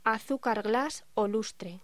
Título Locución: Azúcar glas o lustre